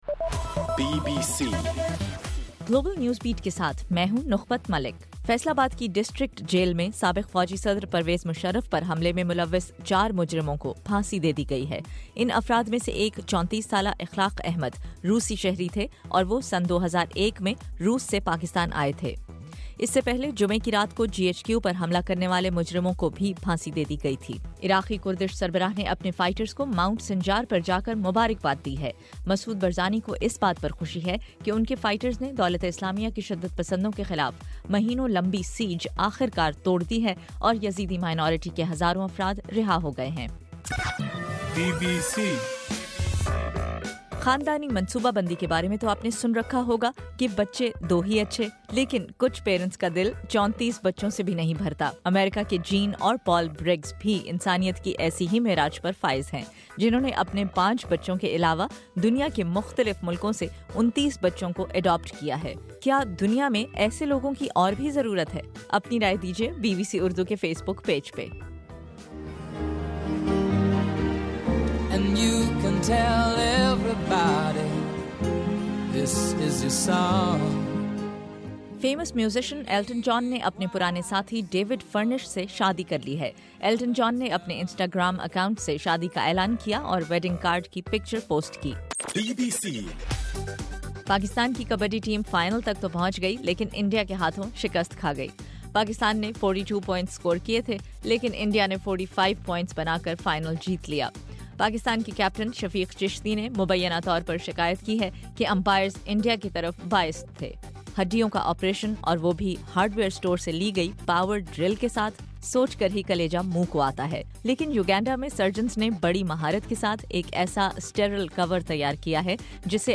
دسمبر22: صبح 1 بجے کا گلوبل نیوز بیٹ بُلیٹن